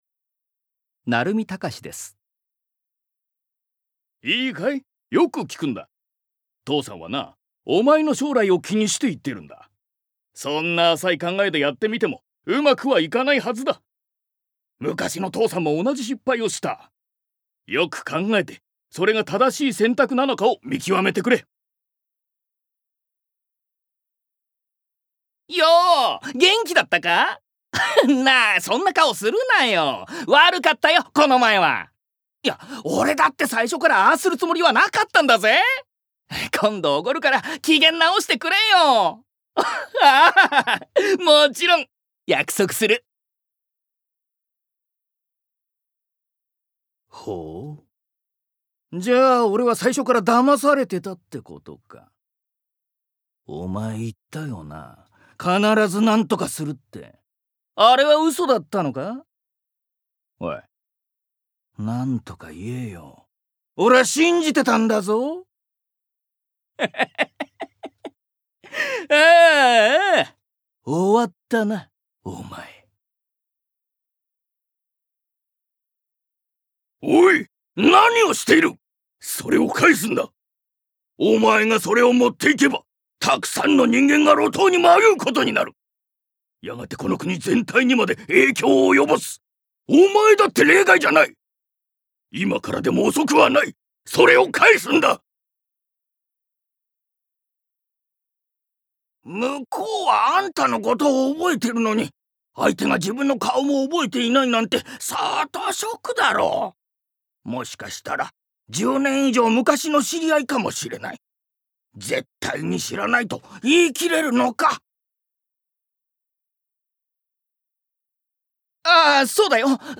Voice Sample